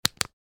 Flashlight On.ogg